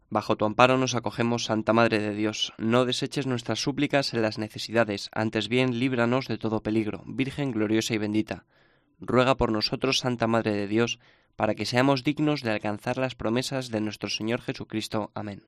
Invocación Suub Praesidium, pedida por el Papa Francisco al terminar el Rosario